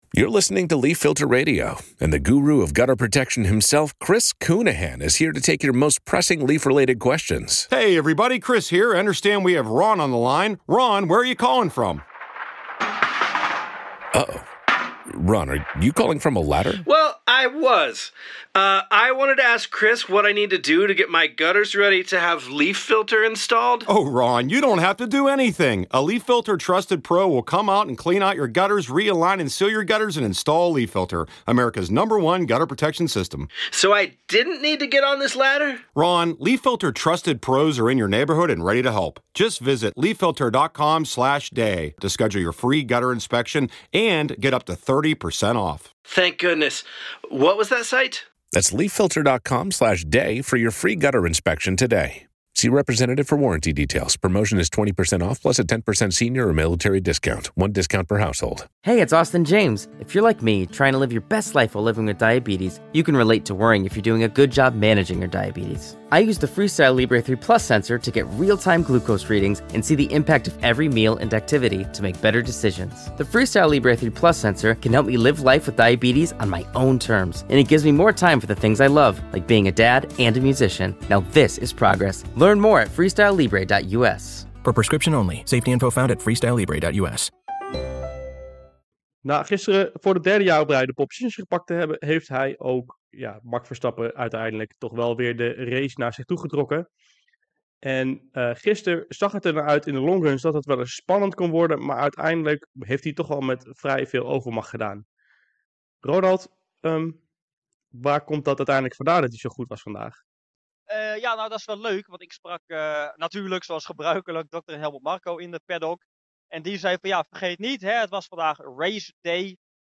bespreken het uitgebreid in een nieuwe aflevering van de F1-update vanuit Suzuka